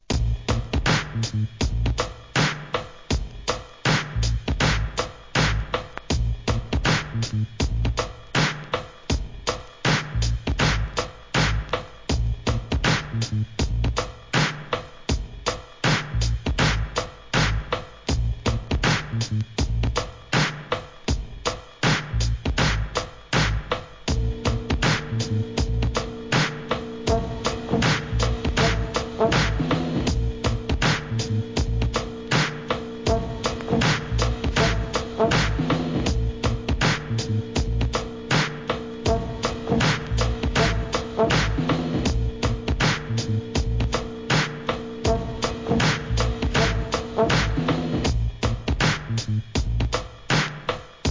HIP HOP/R&B
クロスオーバーするAbstract、ブレイクビーツ!!